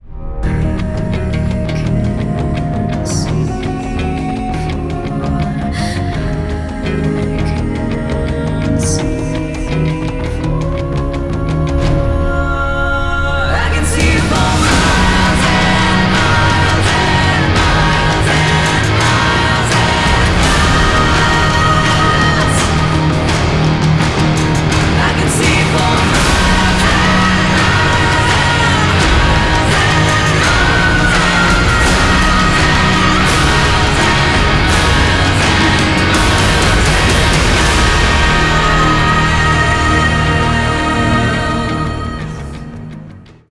Epic/Cinematic cover